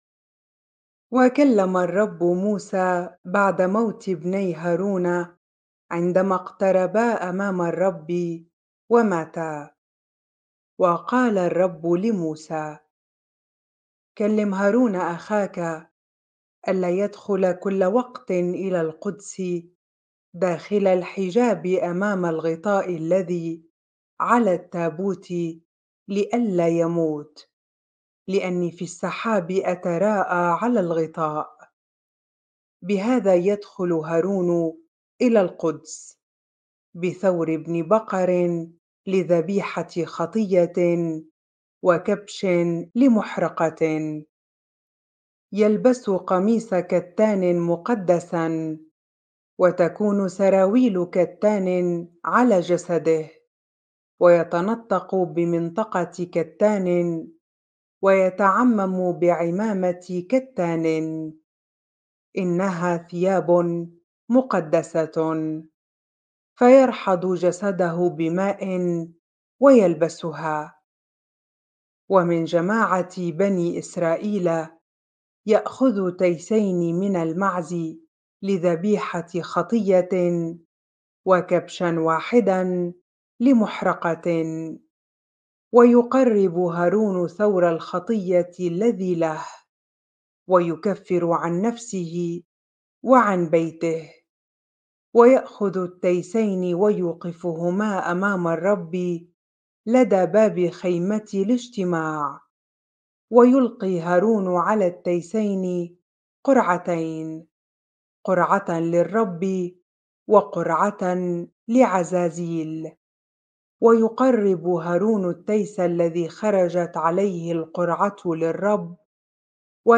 bible-reading-leviticus 16 ar